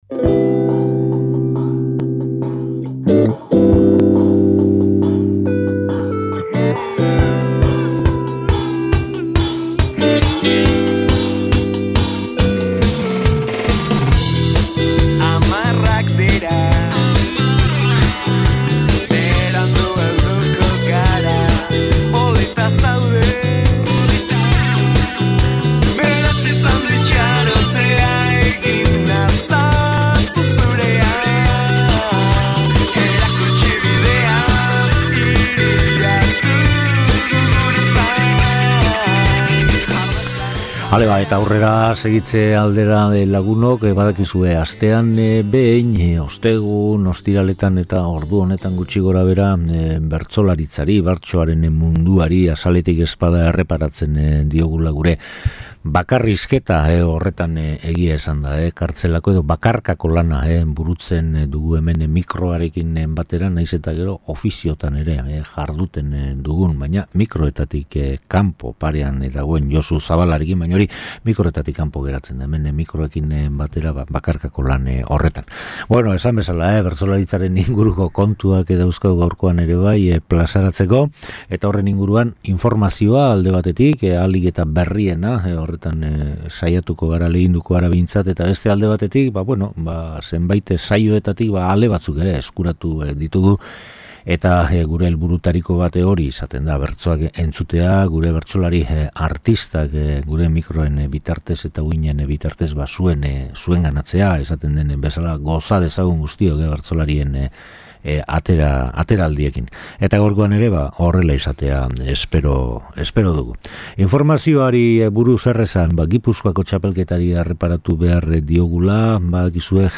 SOLASALDIA
Eta arteetan bertsolariak entzungai